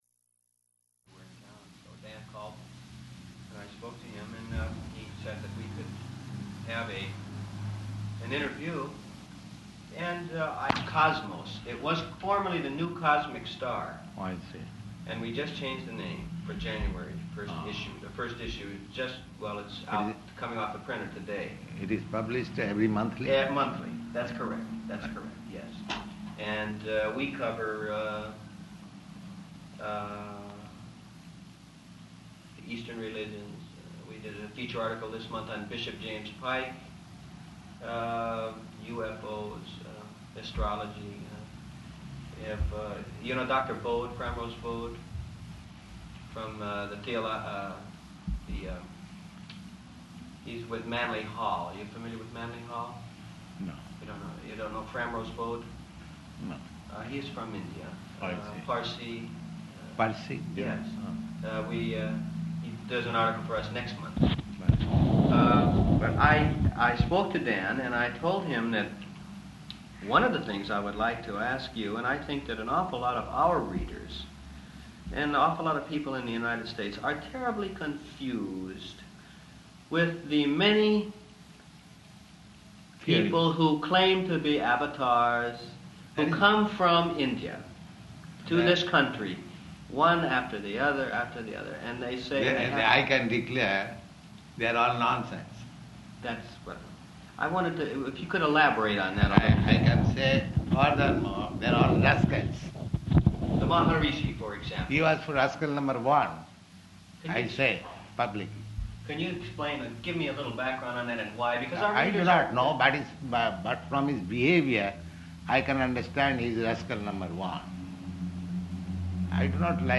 Press Interview
Press Interview --:-- --:-- Type: Interview Dated: December 30th 1968 Location: Los Angeles Audio file: 681230IV-LOS_ANGELES.mp3 Journalist: ...and I spoke to him, and then he said we could have an interview, and...